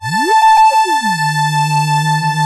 OSCAR 10 A5.wav